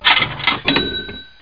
moneywin.mp3